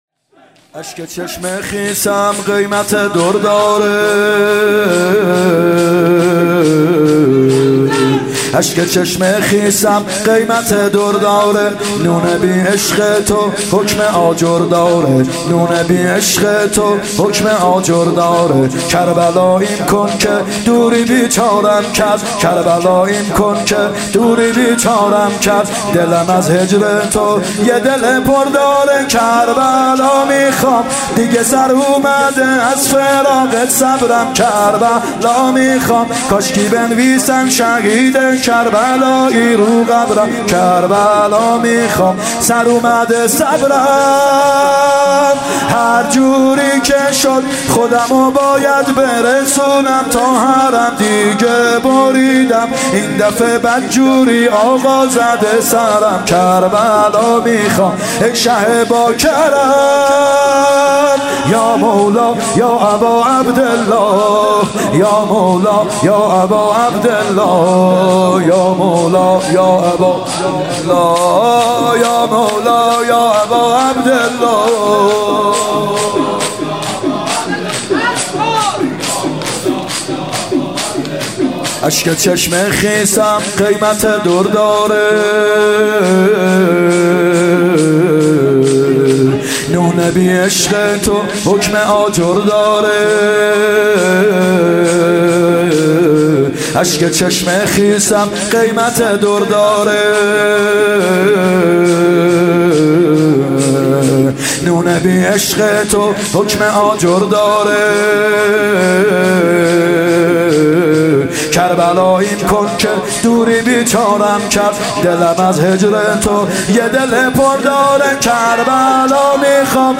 مراسم هفتگی 950501 شور ( اشک چشم خیسم قیمت در داره